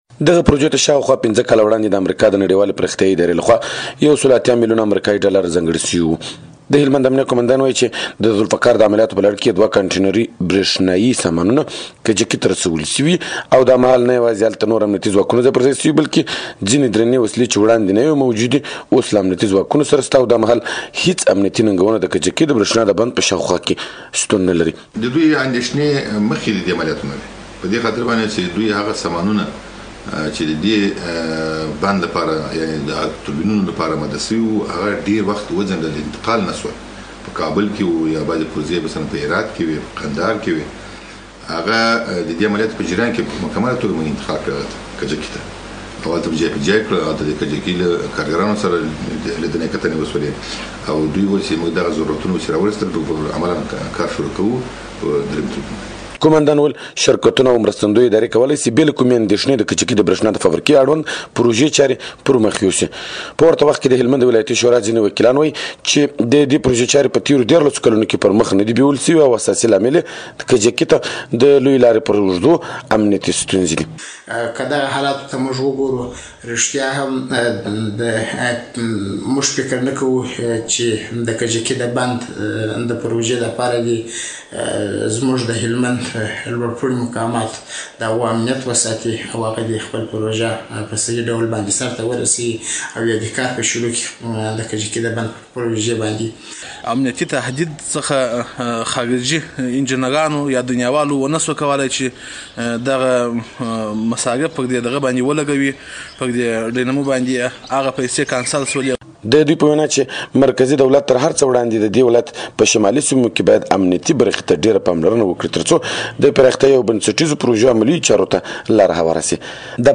راپورونه